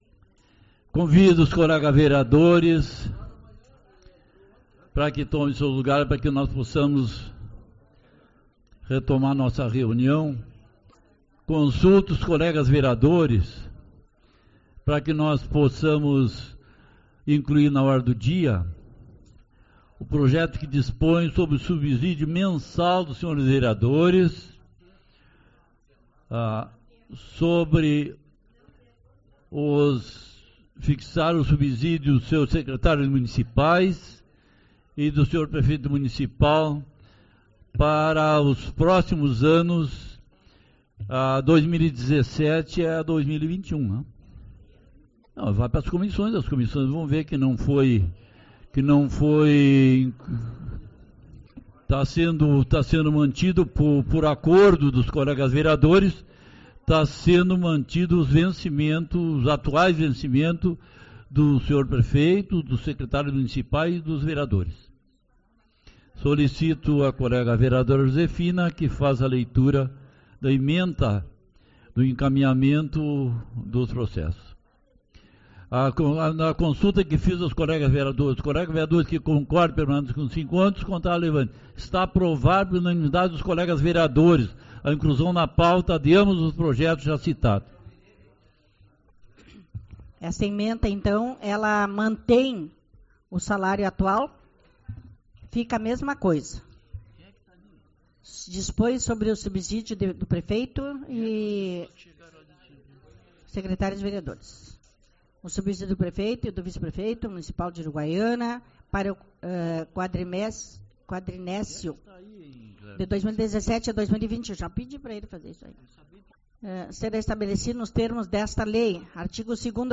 31/08 - Reunião Ordinária (continuação)